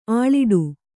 ♪ āḷiḍu